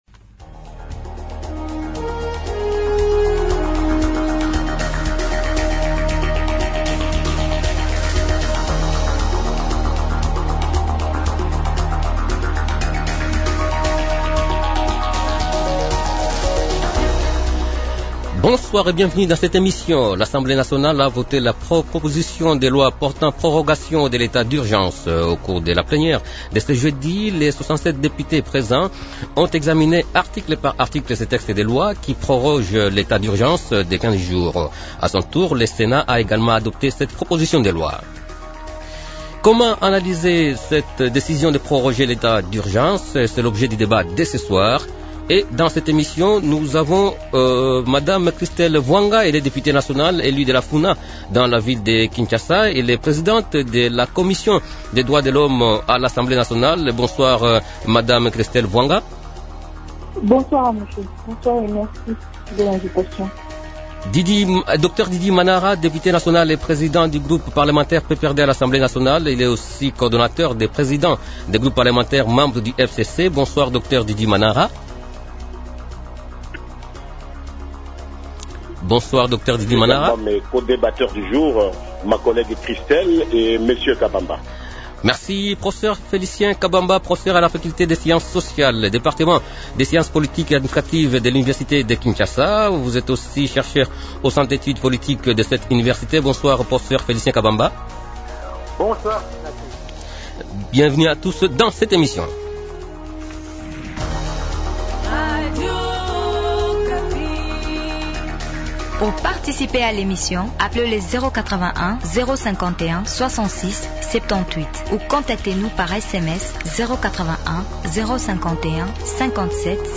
Comment analyser cette décision de proroger l’état d’urgence ? Invités Dr Didi Manara, Député national et président du groupe parlementaire PPRD à l’Assemblée nationale.
Christelle Vuanga, Députée nationale, élu de la Funa dans la ville de Kinshasa.